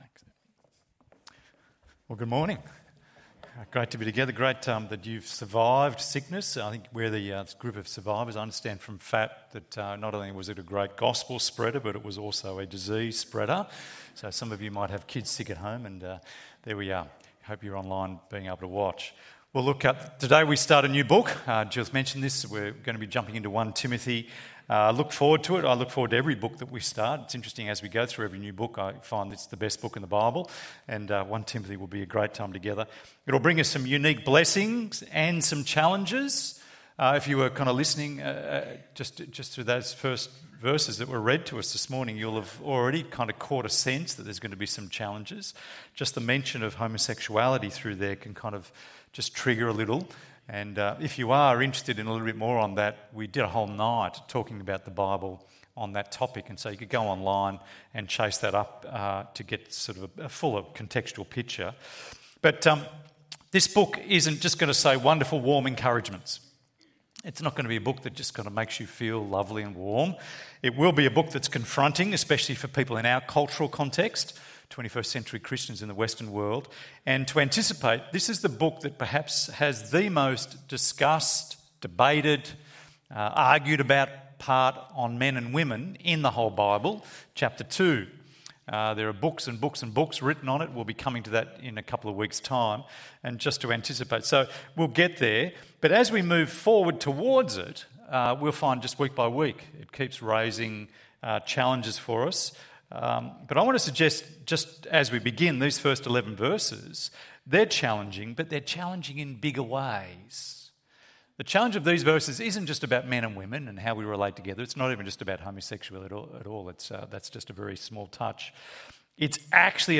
Stay in Ephesus! ~ EV Church Sermons Podcast